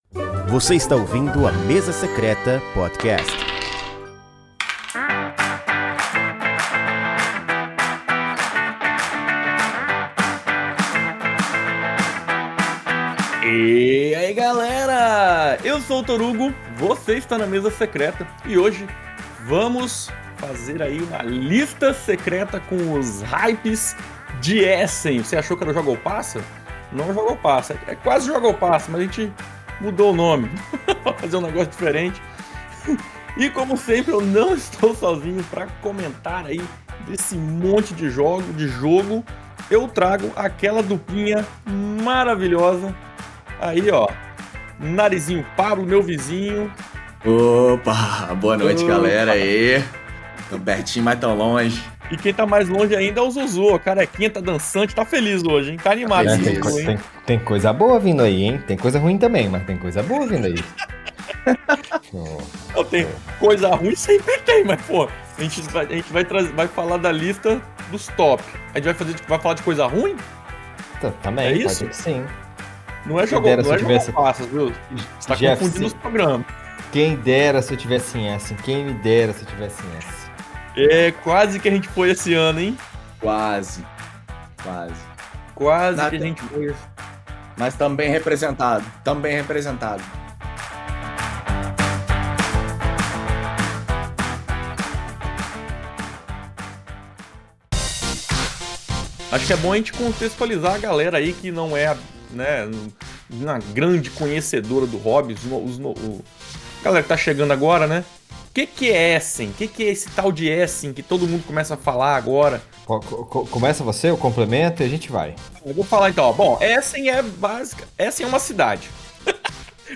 Os 15 jogos mais comentados pela comunidade internacional estarão presentes neste super bate papo! Este podcast foi feito a partir de nossa live no Youtube.